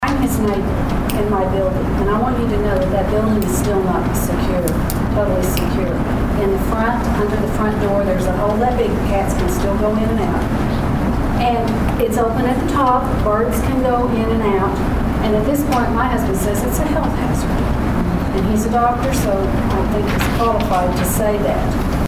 At a Tuesday evening city council meeting